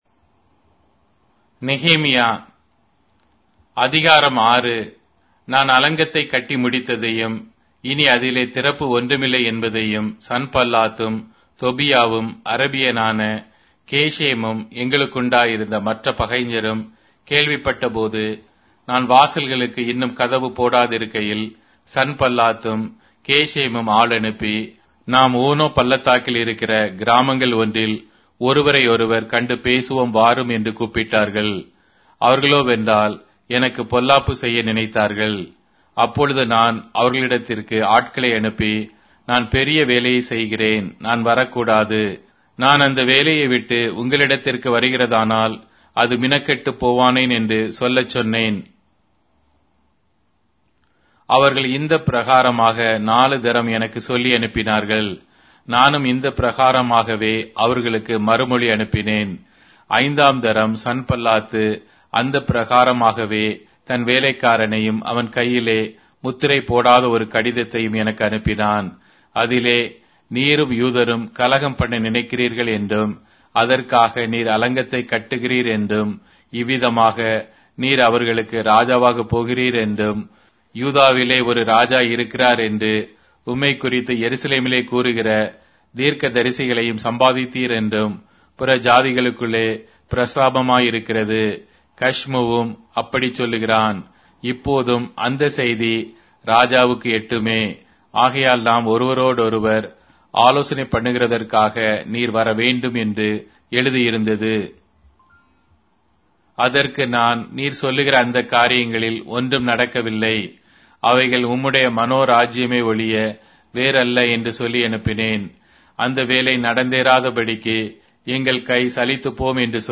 Tamil Audio Bible - Nehemiah 9 in Guv bible version